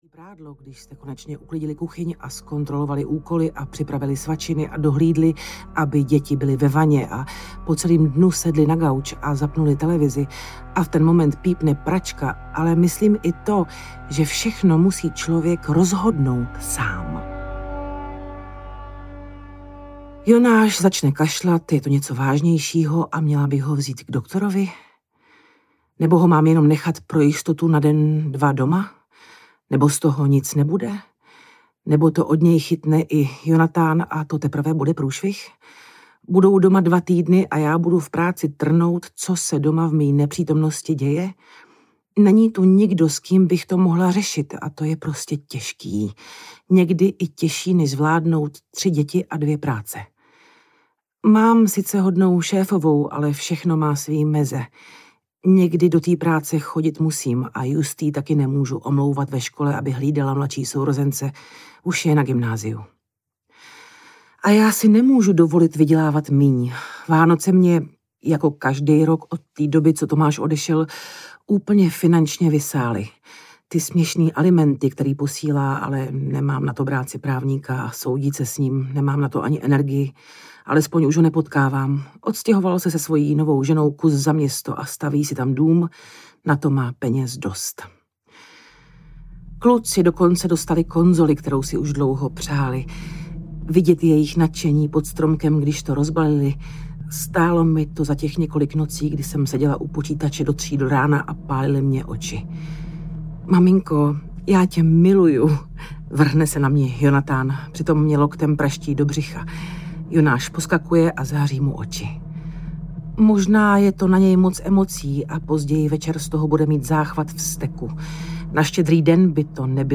Nic nebude jako dřív audiokniha
Ukázka z knihy